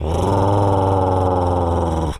wolf_growl_03.wav